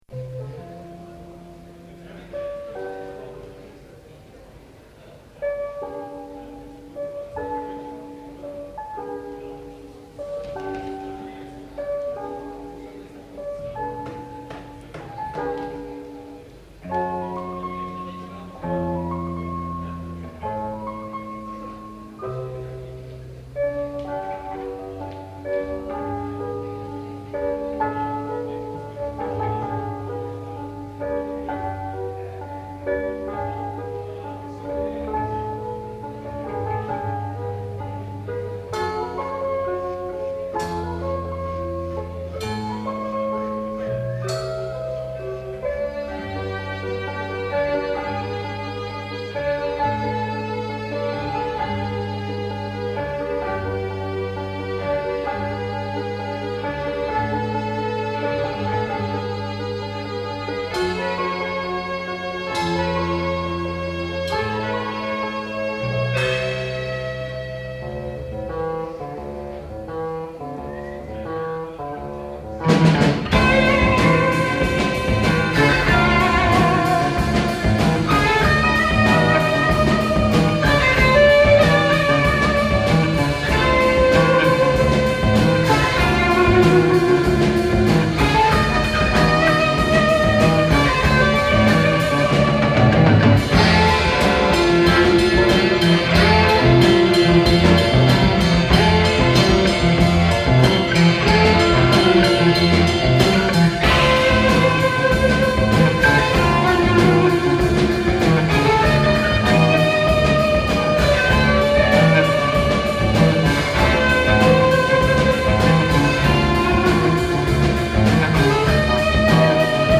( recorded LIVE - Zeltgasse  6.Sept. 1978 )